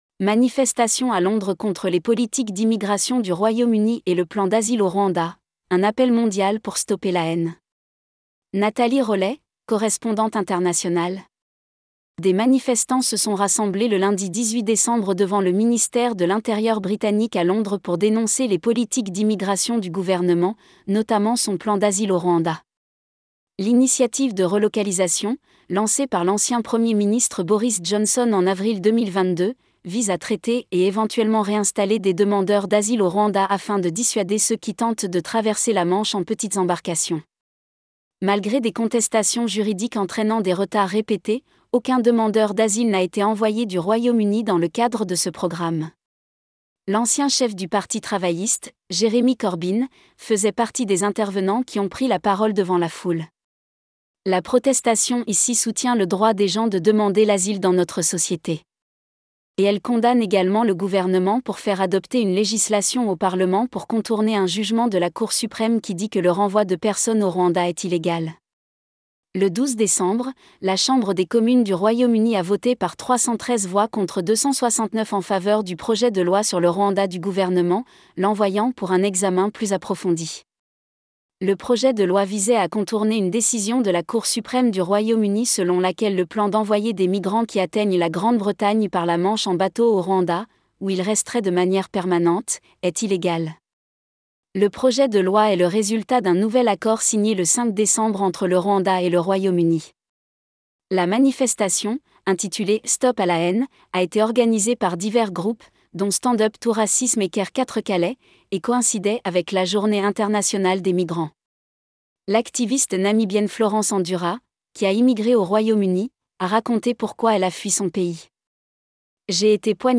Des manifestants se sont rassemblés le lundi 18 décembre devant le ministère de l’Intérieur britannique à Londres pour dénoncer les politiques d’immigration du gouvernement, notamment son […]